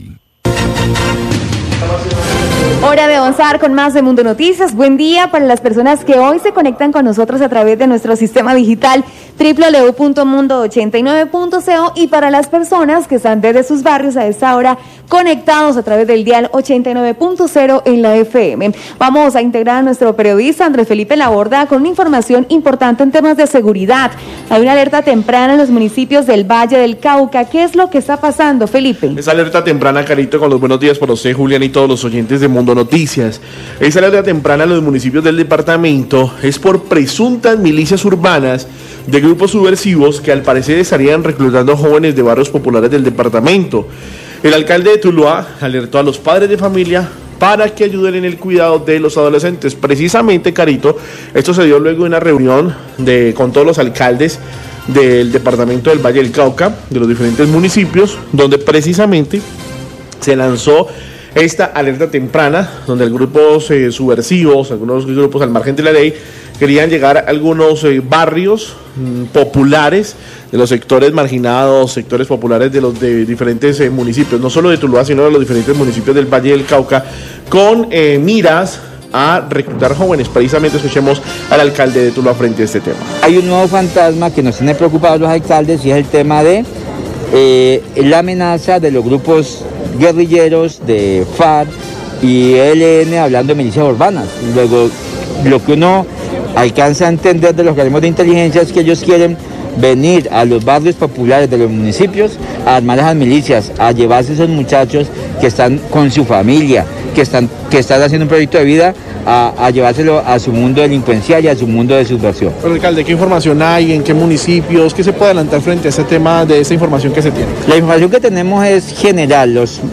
Radio
Alerta en los municipios del departamento del Valle del Cauca por el reclutamiento de menores por parte de grupos armados ilegales como las Farc y el Eln, esto con el propósito de formar milicias urbanas. El alcalde de Tuluá habla del tema y le pide a los padres estar muy atentos a lo que hacen sus hijos.